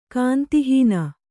♪ kāntihīna